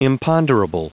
Prononciation du mot imponderable en anglais (fichier audio)
Prononciation du mot : imponderable